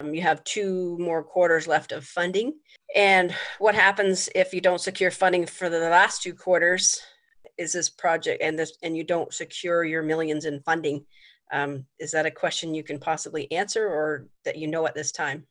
The sudden increase in the cost had Quinte West city councillors asking questions. Councillor Leslie Roseblade expressed some concern.